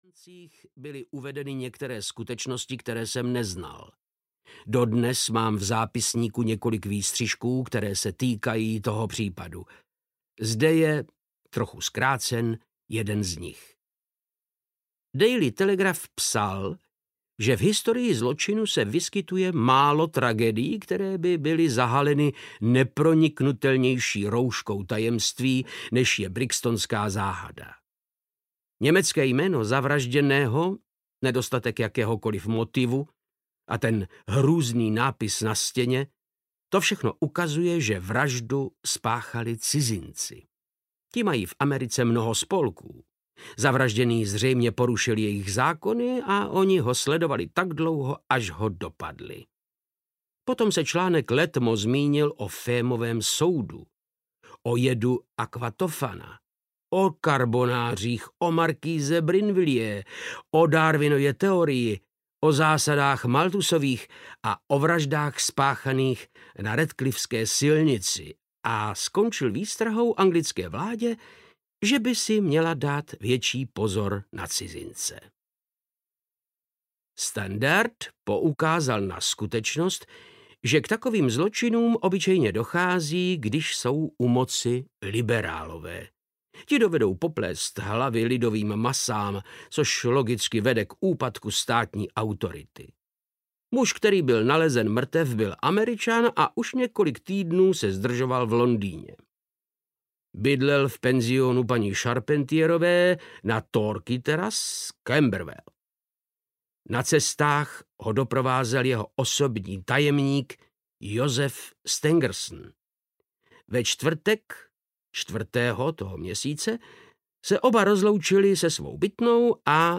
Studie v šarlatové – část 3. audiokniha
Ukázka z knihy
• InterpretVáclav Knop